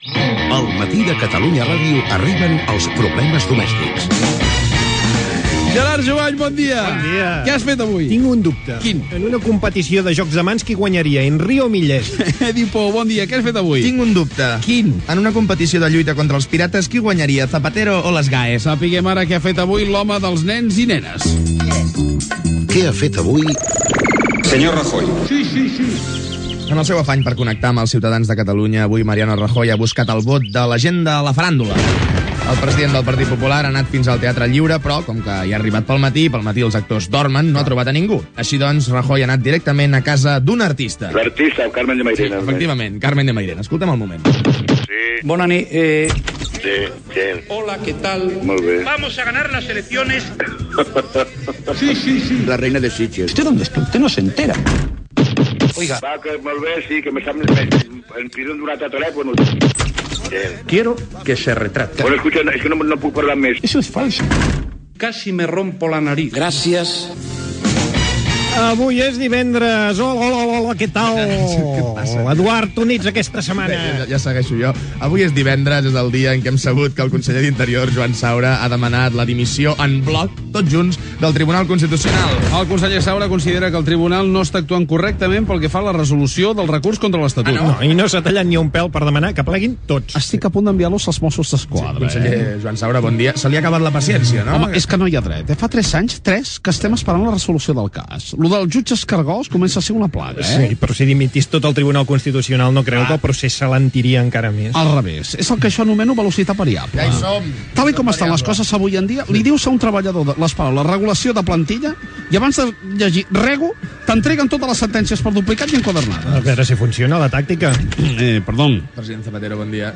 Fragment de la secció "Problemes domèstics". Indicatiu de la secció, dos dubtes inicials, què ha fet avui el president del govern Mariano Rajoy, el conseller d'Interior Joan Saura i el Tribunal Constitucional, etc.
Info-entreteniment